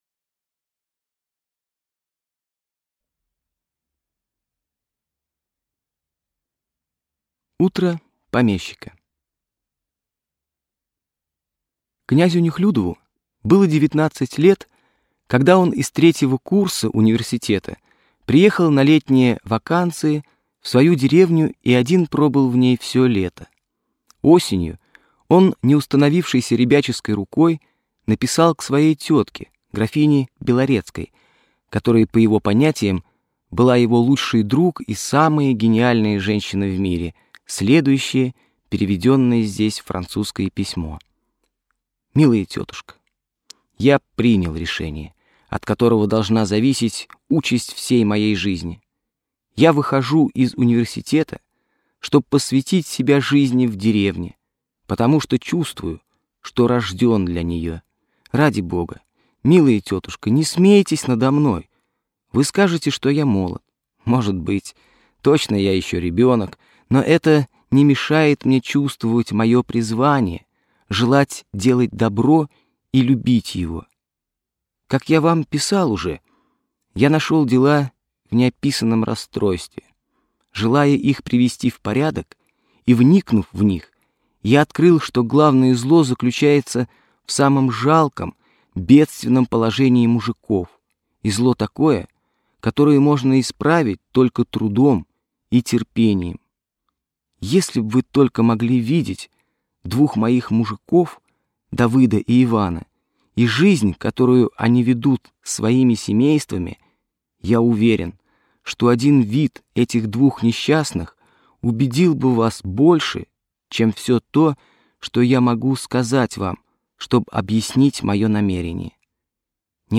Аудиокнига Утро помещика | Библиотека аудиокниг